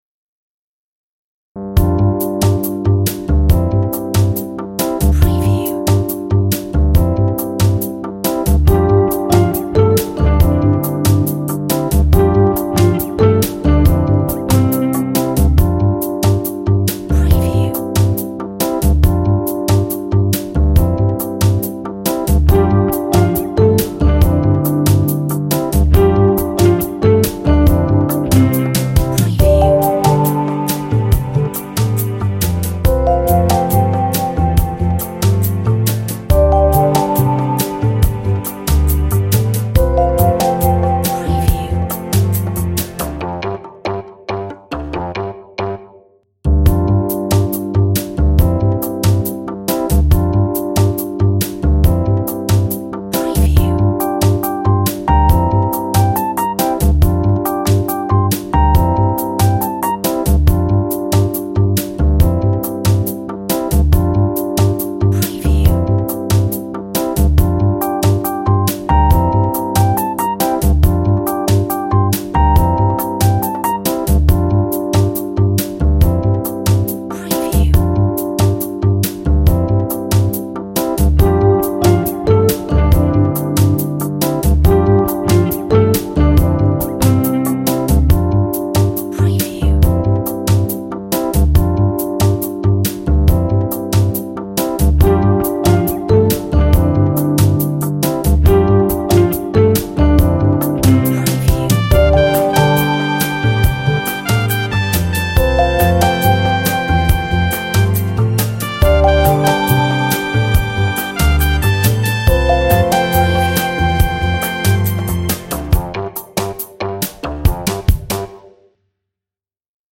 Funky chilled